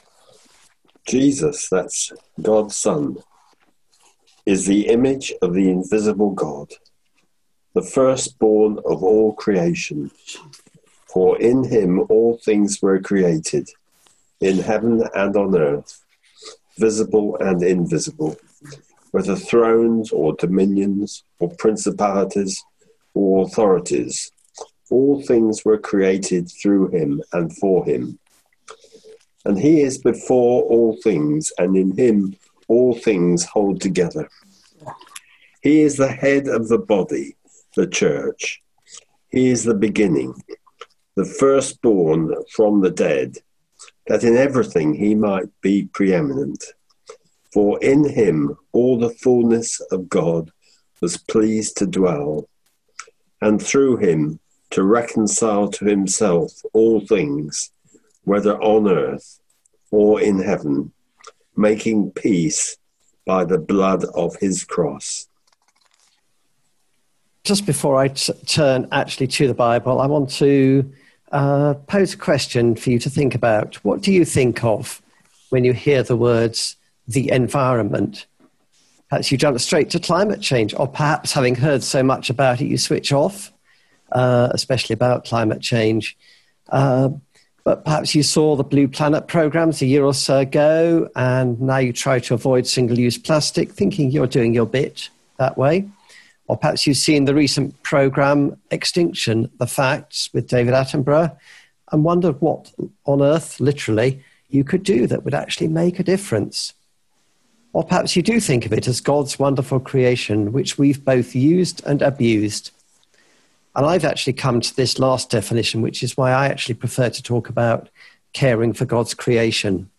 The recording starts with a reading from Colossians 1 v15 onwards.